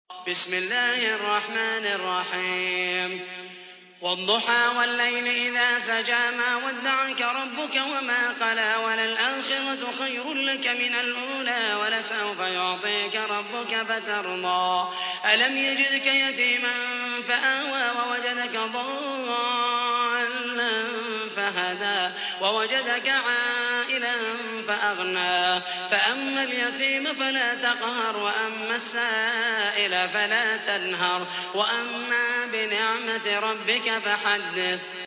تحميل : 93. سورة الضحى / القارئ محمد المحيسني / القرآن الكريم / موقع يا حسين